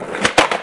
滑板 " 回弹
描述：滑板弹跳研磨
标签： 弹跳 研磨 滑板
声道立体声